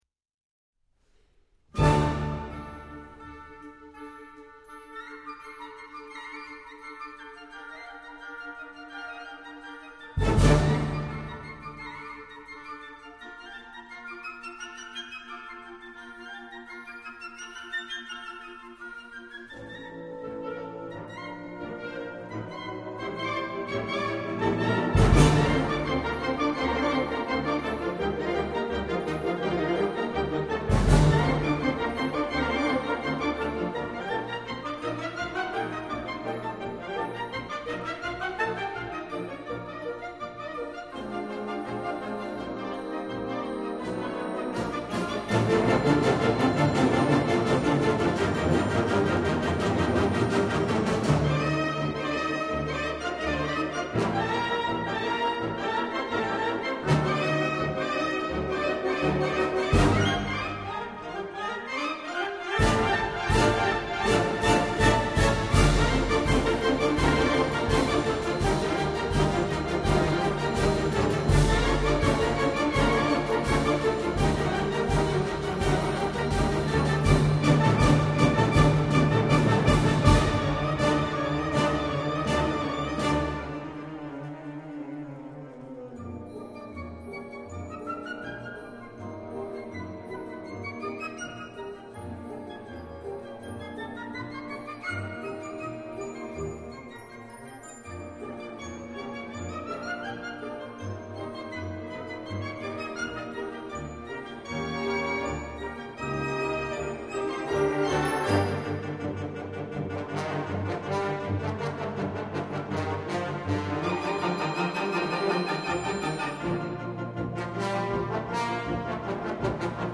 D minor（原調）
注：　正確には短調ではなくフリギア旋法 (D-E♭-F-G-A-B♭-C) です。